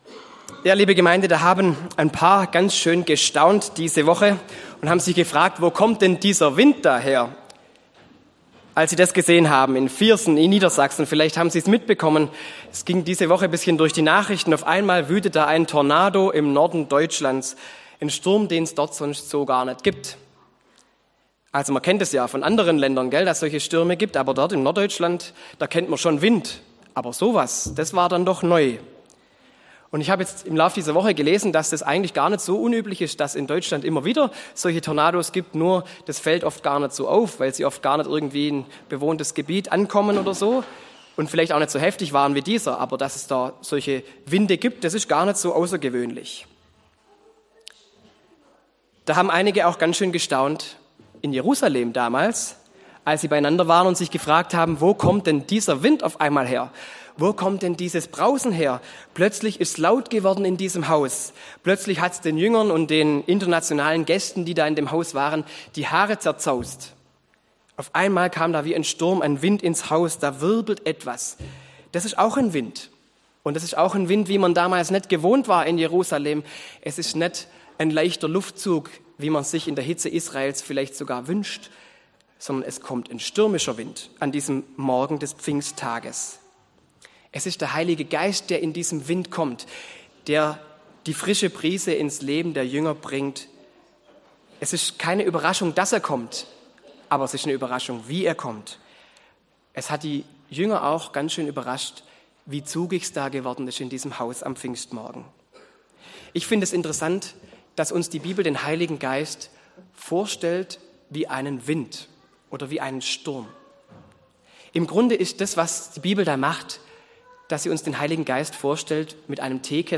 predigt-an-pfingsten-wie-der-wind